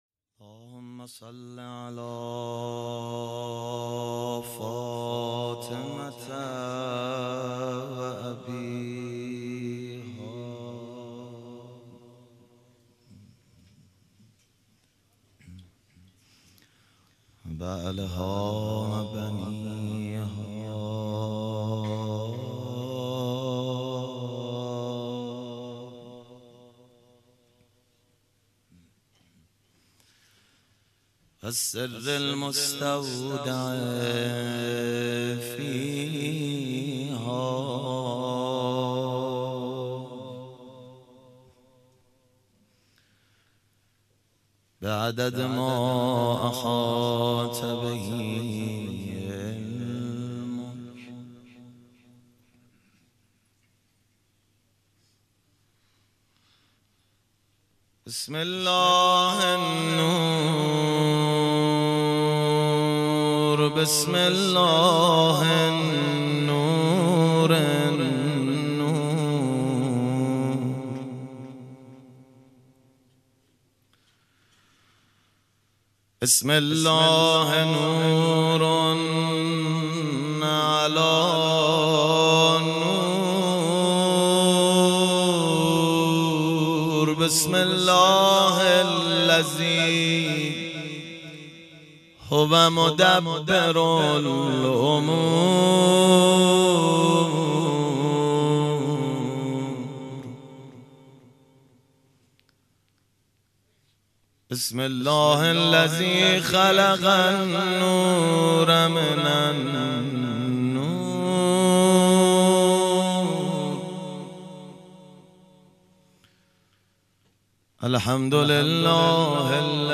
دانلود مداحی دعایی زیر لب دارم شبانه - دانلود ریمیکس و آهنگ جدید